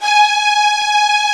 STR STRING0C.wav